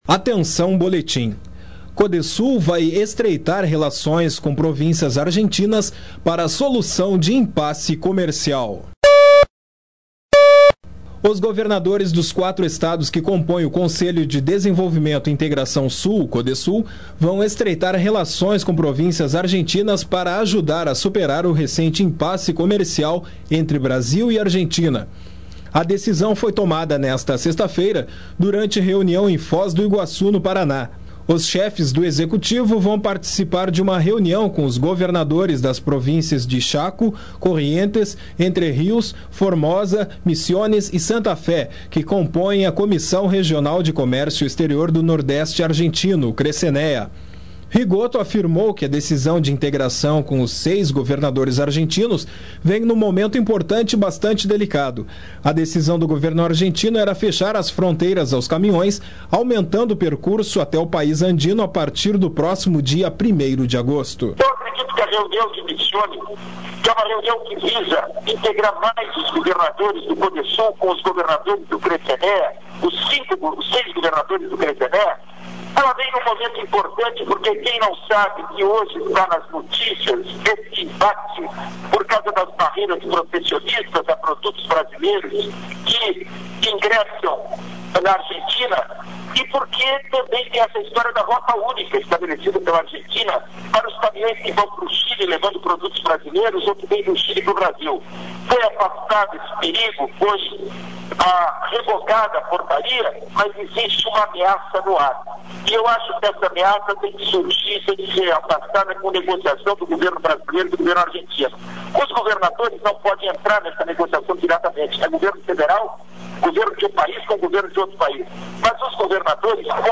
Os governadores dos quatro estados que compõem o Codesul vão estreitar relações com províncias argentinas para ajudar superar o recente impasse comercial entre Brasil e Argentina. Sonora: governador Germano Rigotto, em Foz do Iguaçu (PR).Local: Porto A